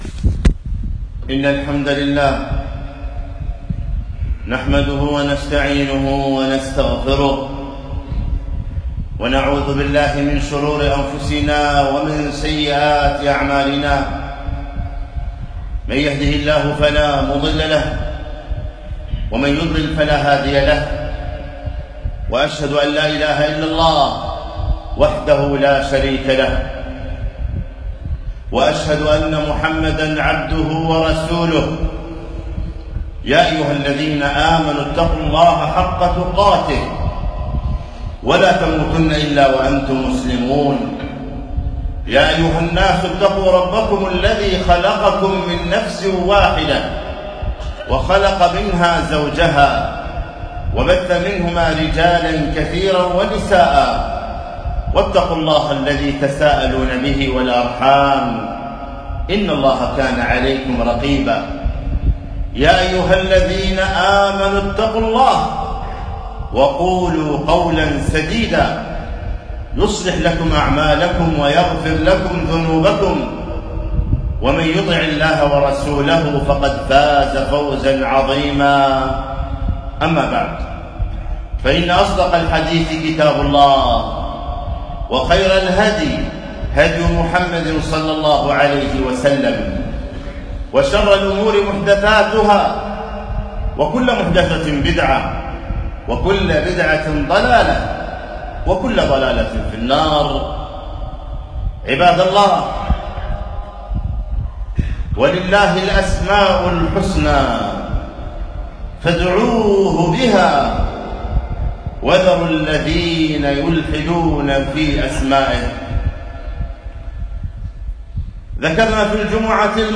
خطبة - إرشاد الحذاق إلى اسم الله الرزاق